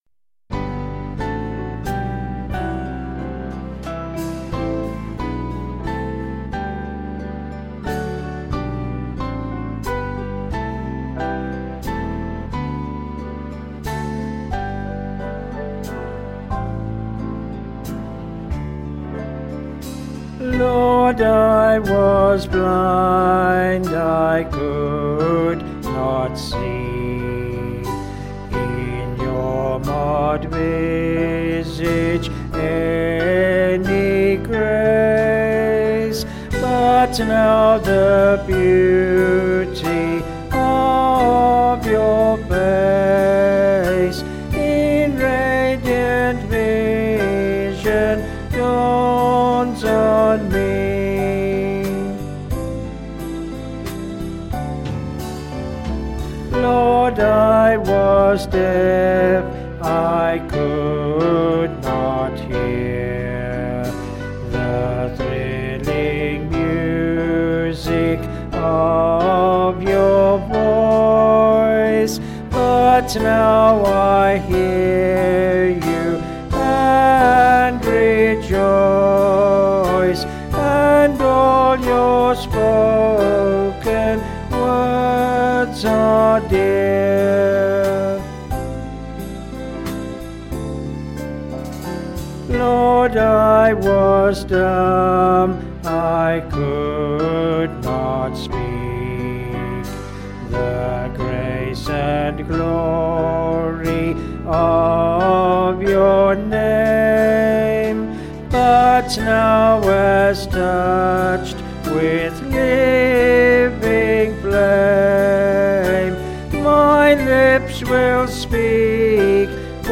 Vocals and Band   264.2kb